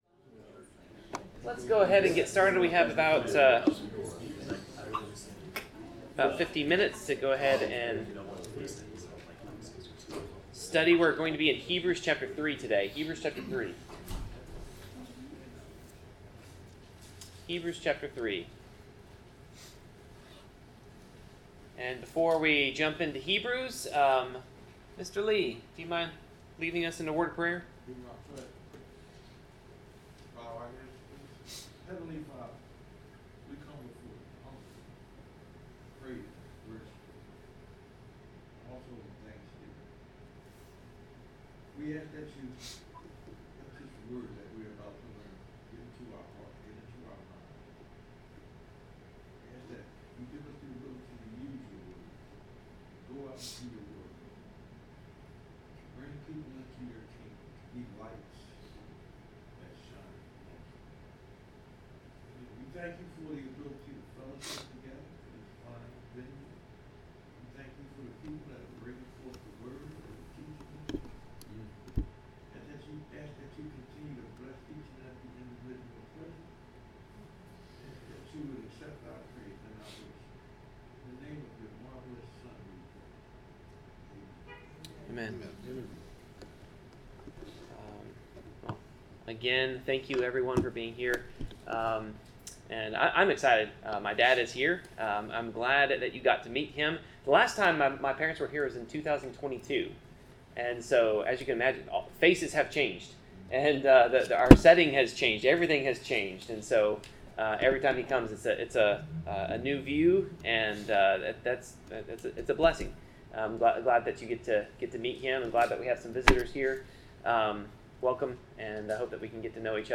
Passage: Hebrews 3, Psalm 95 Service Type: Bible Class